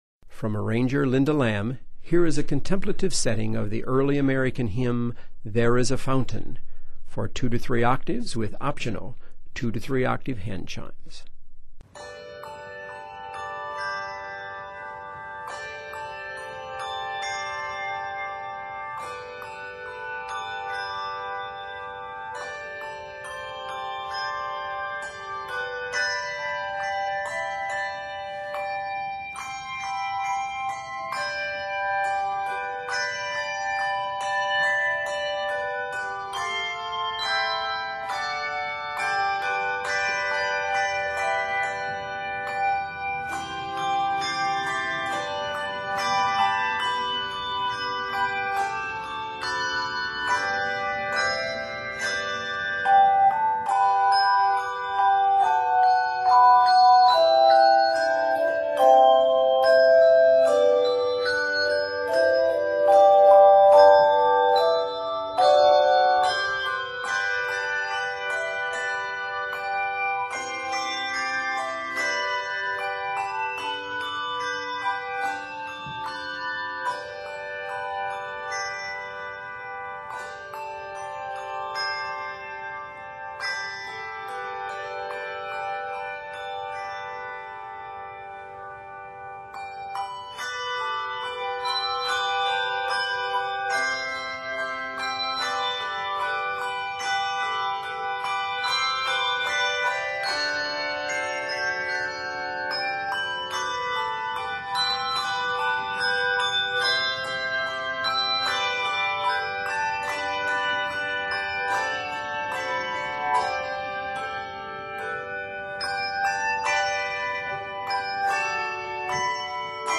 contemplative setting of the Early American hymn tune